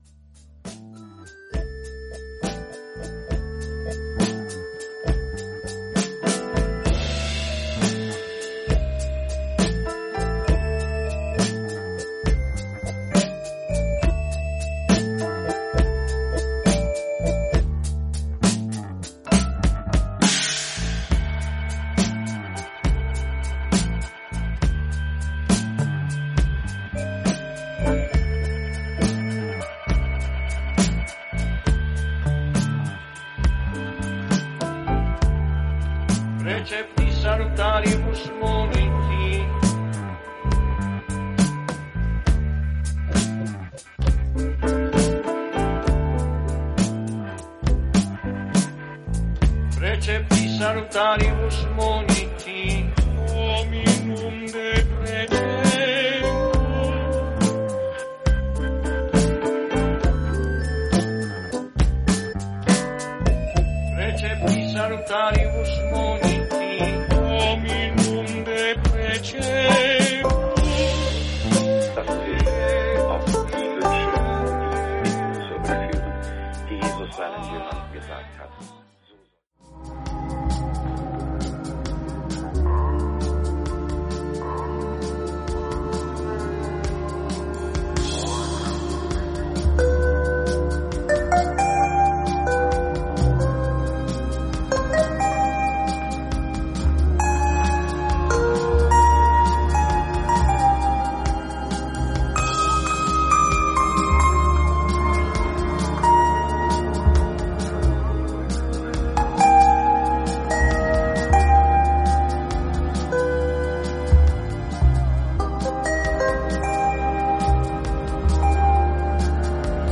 シンセのリフと浮遊感のあるギターが心地良い
グレゴリオ聖歌をコラージュし
ユーモラスでトロピカルなニュー・ウェーブ・サンバ